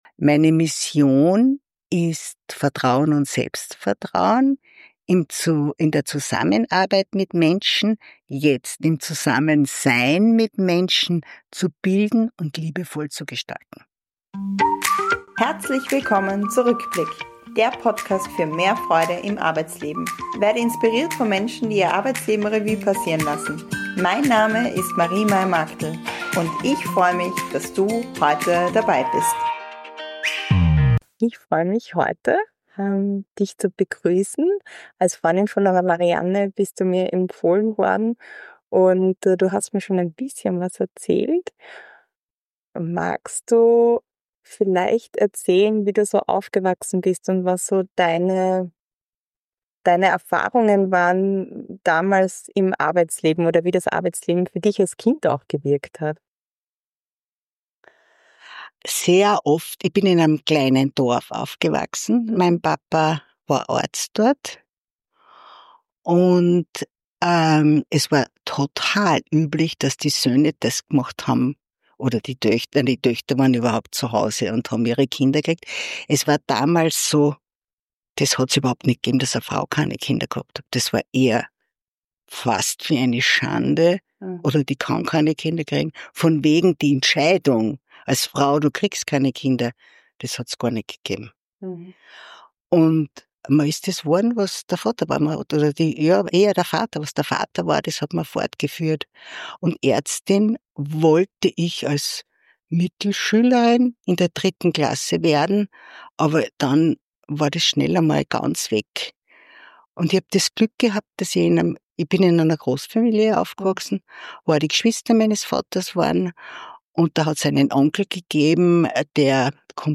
Fazit: Dieses Gespräch ist eine Einladung, das Leben als Spielwiese zu betrachten und die eigene Stimme ernst zu nehmen.